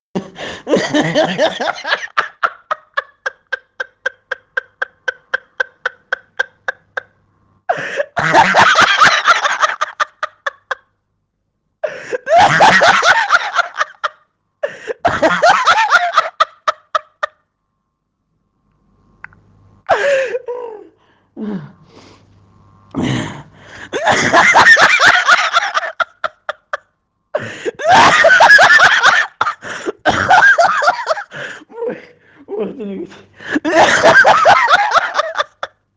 SD hilarious laugh